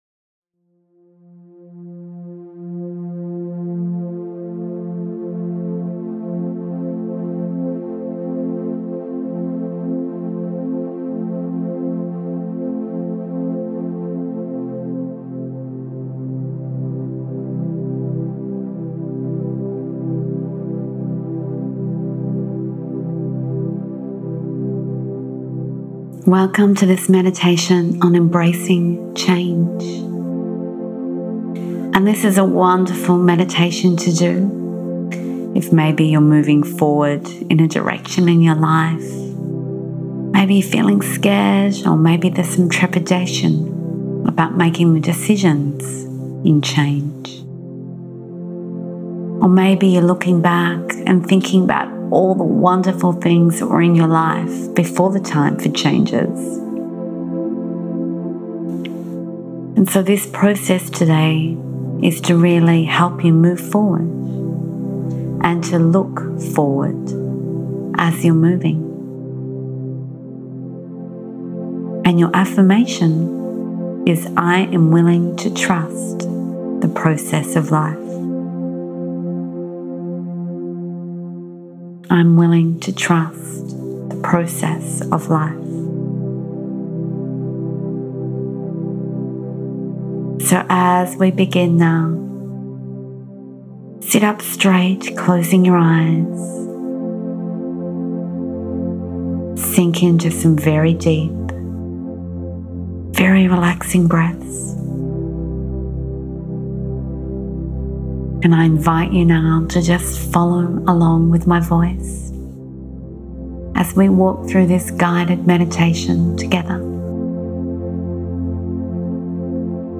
Listen to our free healing tools